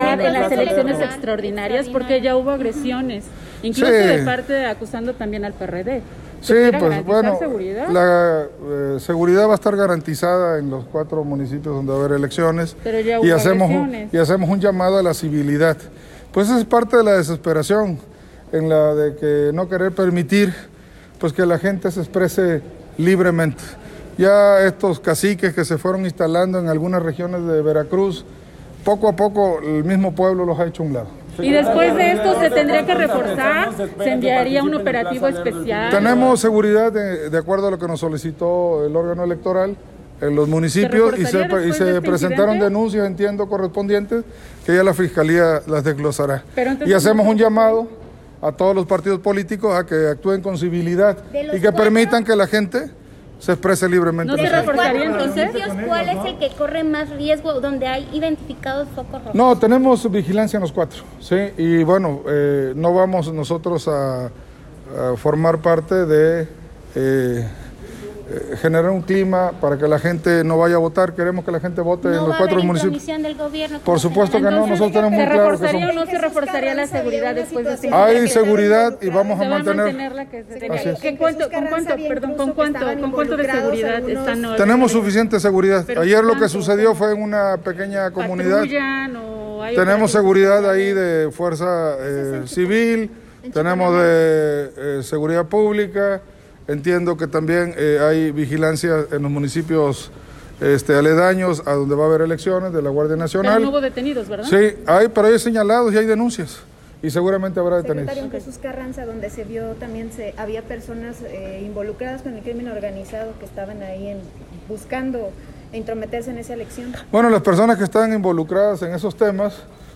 Durante entrevista realizada en Palacio de Gobierno, el funcionario estatal se refirió a los hechos donde fueron atacados militantes de Morena en Chiconamel y confirmó que se presentaron las denuncias correspondientes.